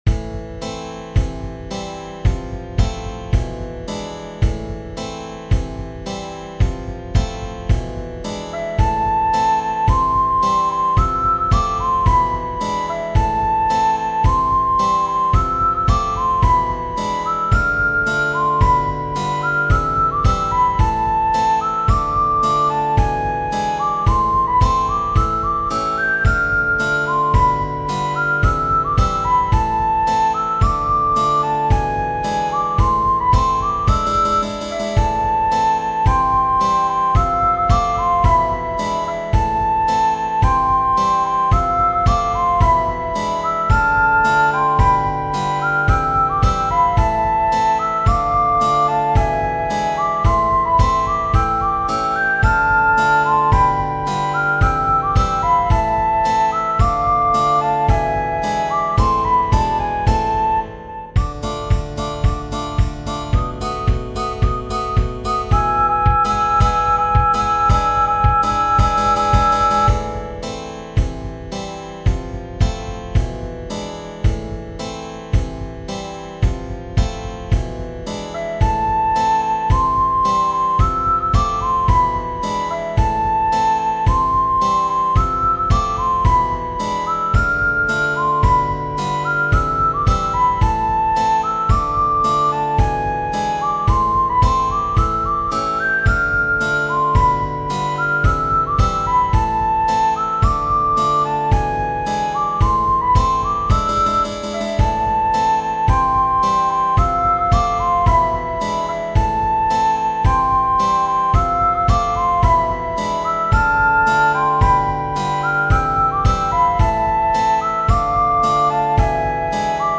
Sociali e Patriottiche